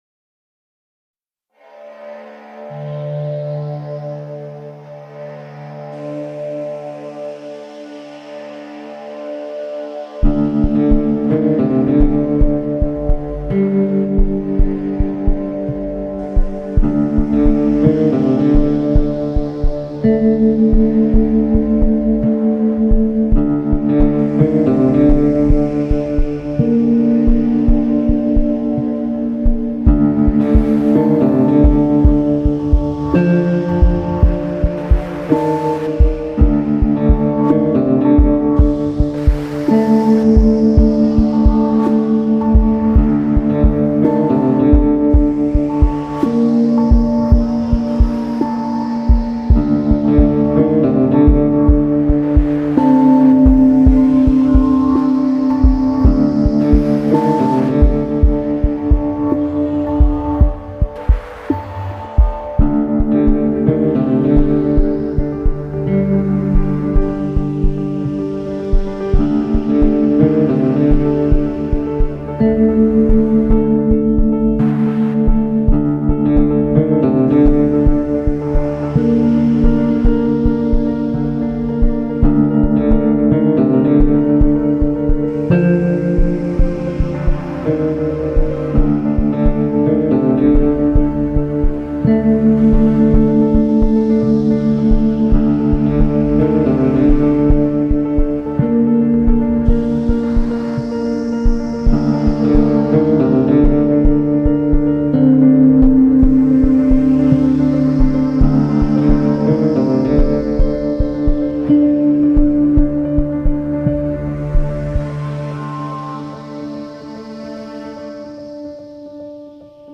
tema dizi müziği, duygusal heyecan gerilim fon müziği.